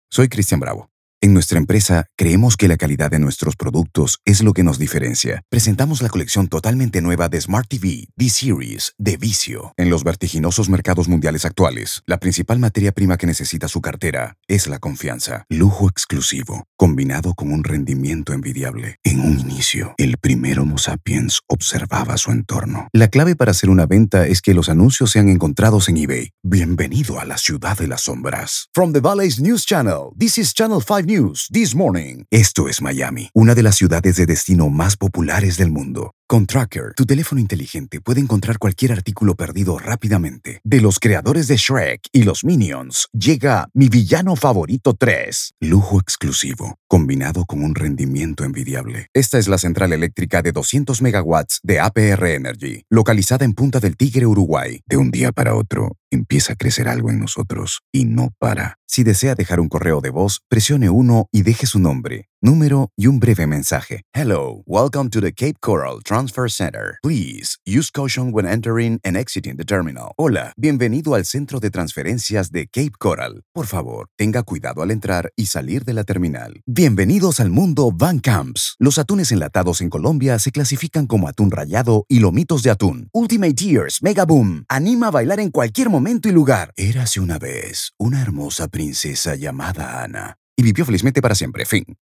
Hola! My voice has a neutral accent so the versatility in its use for all Spanish speaking is assured. Corporate, Romantic, Smooth, Warm and Deep Voice.
Sprechprobe: Industrie (Muttersprache):